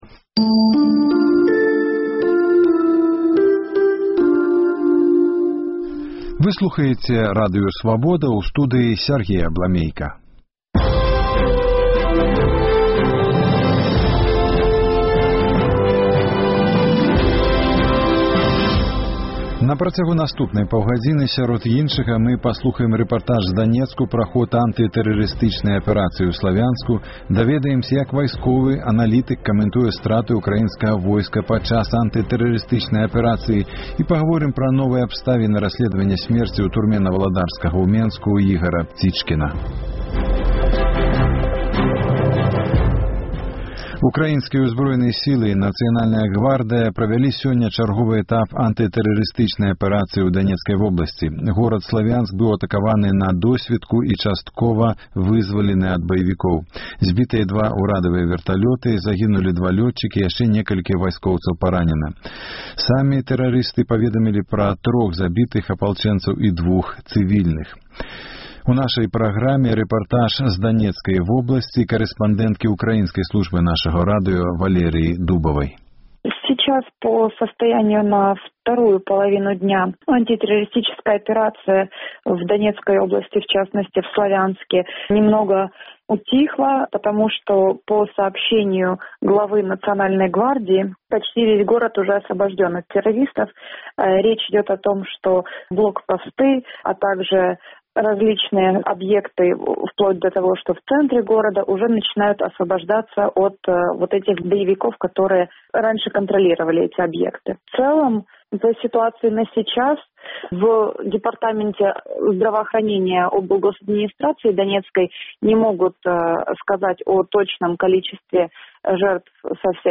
У праграме рэпартаж з Данецку
улады шукаюць наркатычны сьлед Апытаньне ў Гомелі